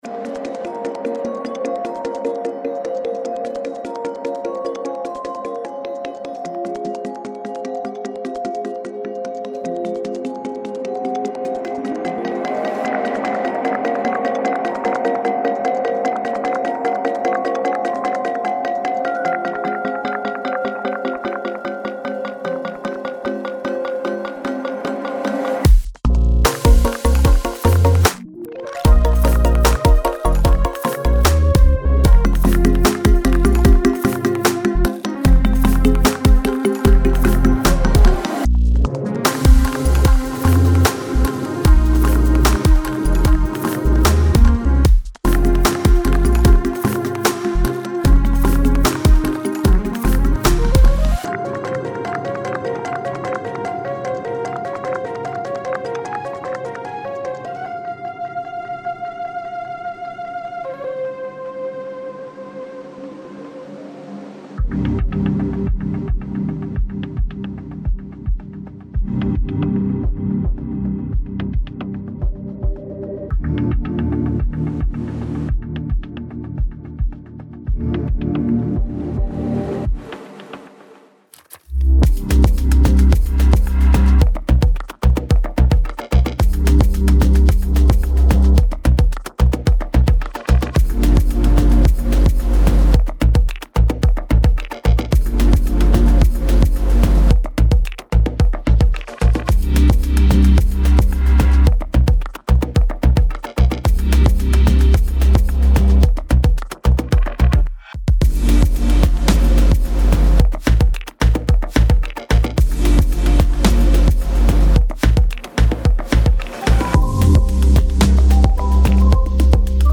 FX / 电影
• 节奏-90、110、130、150BPM